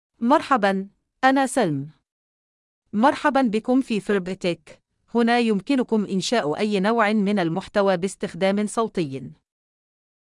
Salma — Female Arabic (Egypt) AI Voice | TTS, Voice Cloning & Video | Verbatik AI
Salma is a female AI voice for Arabic (Egypt).
Voice sample
Listen to Salma's female Arabic voice.
Salma delivers clear pronunciation with authentic Egypt Arabic intonation, making your content sound professionally produced.